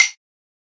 normal-hitnormal.ogg